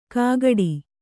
♪ kāgaḍi